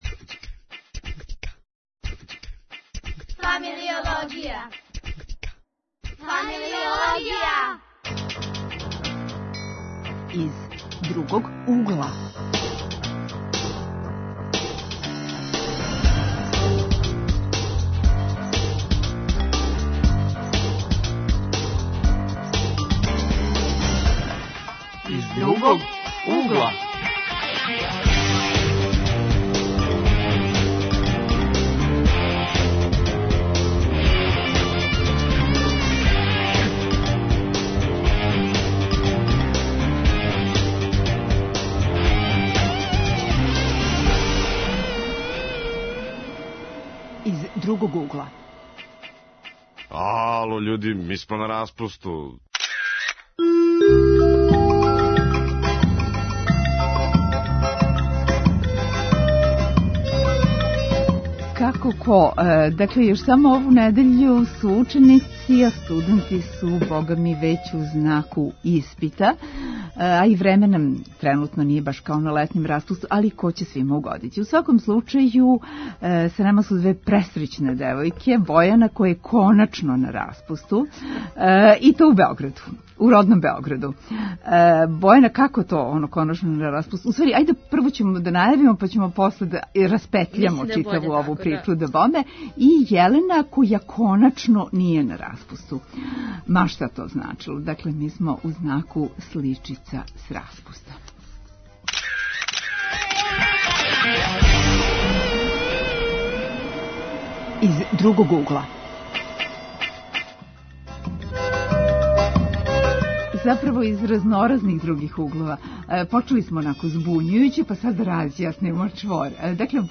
Сличице с распуста, шта издвајају млади из Нове Вароши, Књажевца, Новог Сада… Гости: Млади који студирају у свету, распуст први пут проводе у родном граду